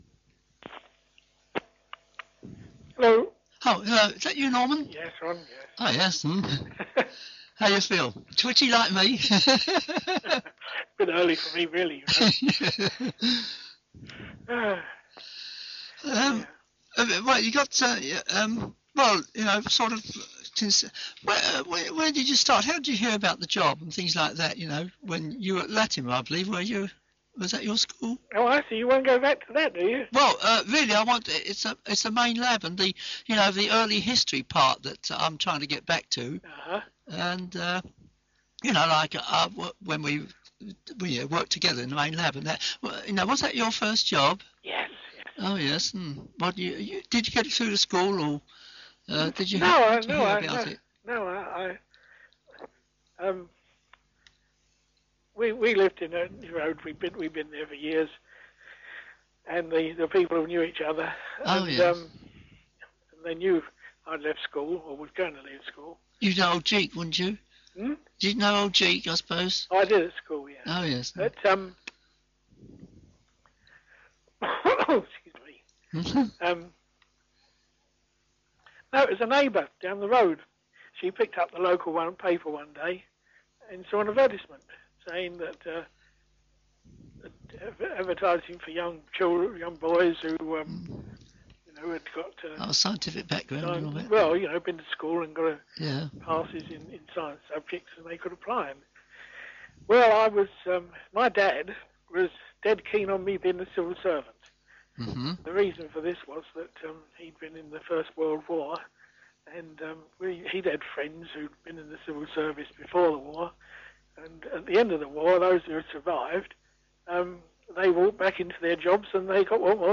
WAOH - Waltham Abbey Oral History